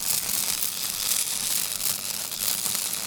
electric_sparks_lightning_loop1.wav